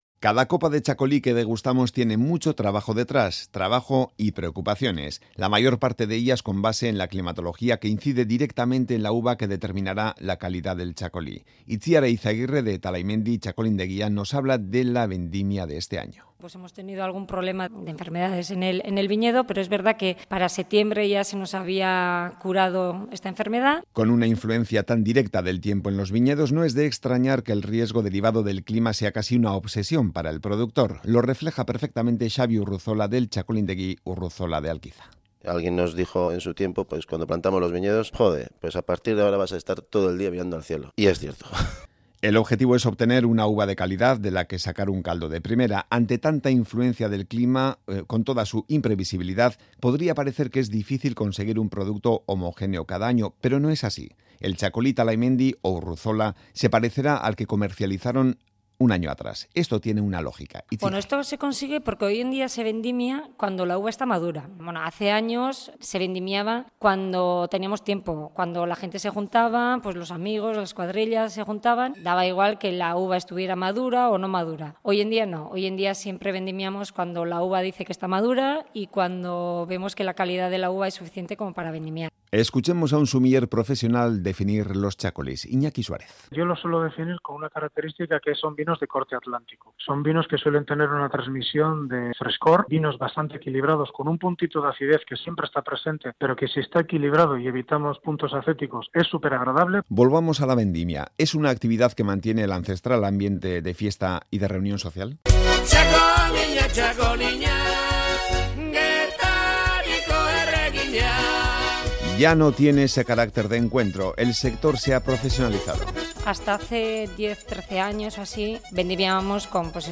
Radio Euskadi REPORTAJES